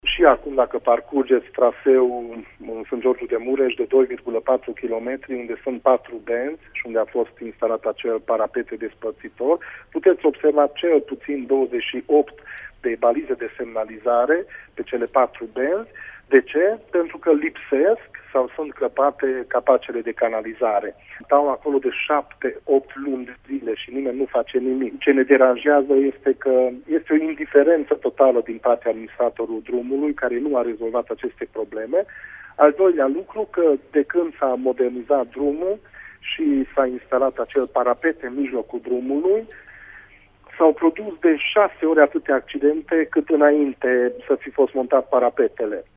Primarul comunei Sângeorgiu de Mureș: